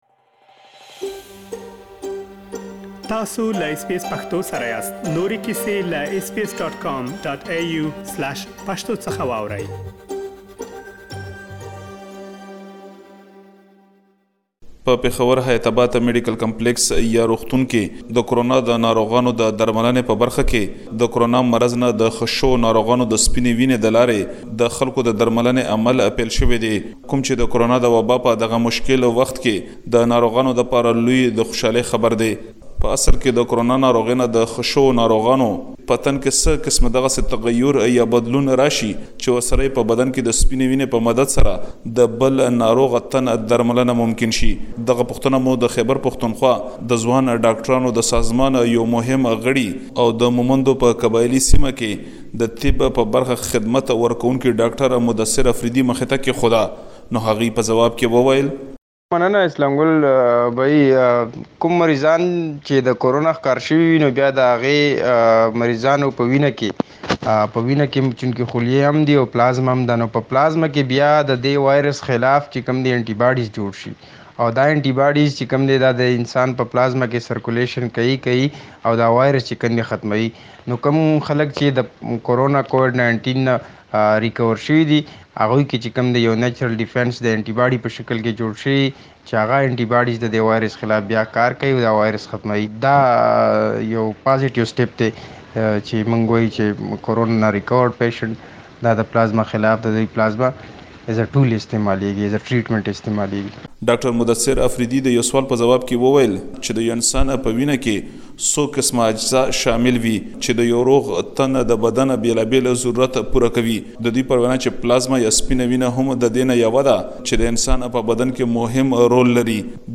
زمونږ همکار د حيات آباد ميډيکل کمپليکس له ډاکټر سره خبرې کړي کوم چې د همدې درملنې برياليتوب په اړه لا ډير معلومات شريکوي، تاسې بشپړه مرکه دلته اوريدلی شئ.